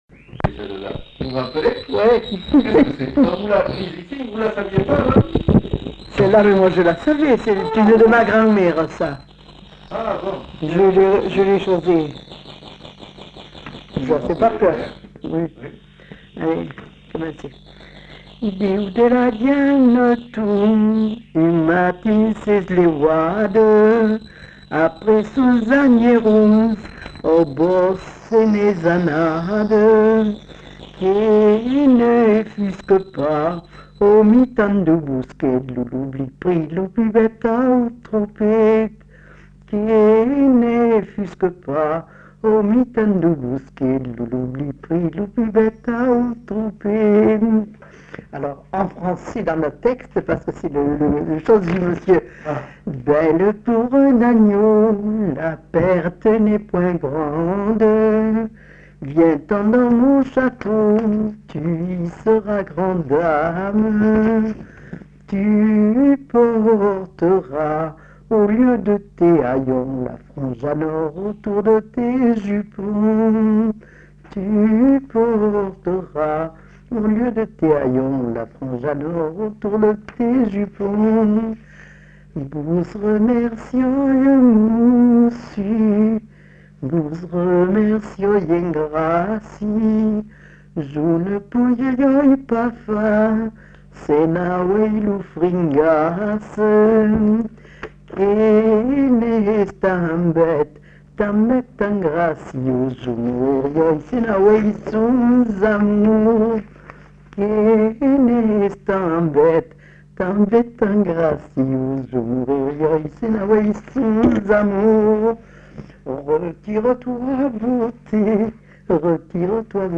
Aire culturelle : Bazadais
Lieu : Grignols
Genre : chant
Effectif : 1
Type de voix : voix de femme
Production du son : chanté